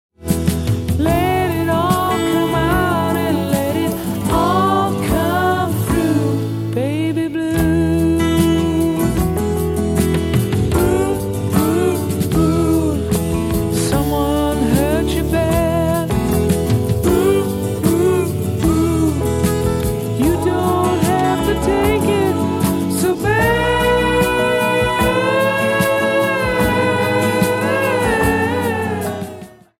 bass, vocals
drums, percussion
guitar, vocals, solina, piano
Album Notes: Recorded at Can-Base Studios, Vancouver, Canada